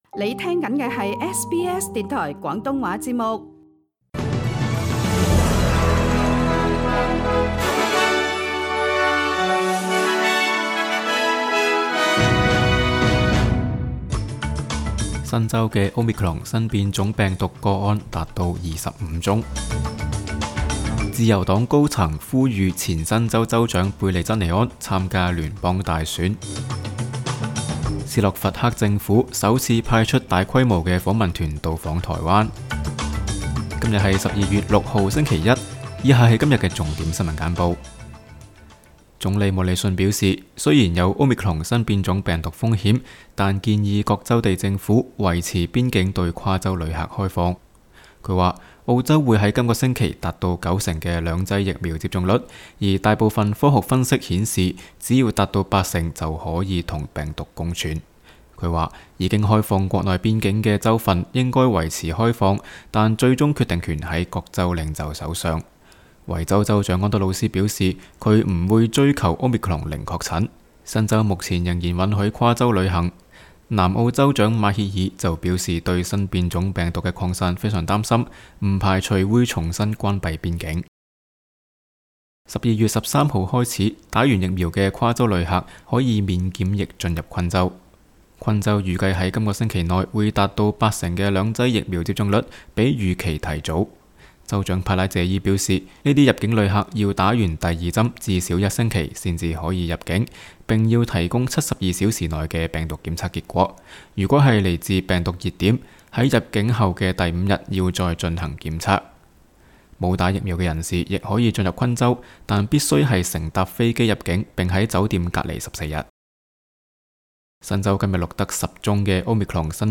SBS 新聞簡報（12月6日）
SBS 廣東話節目新聞簡報 Source: SBS Cantonese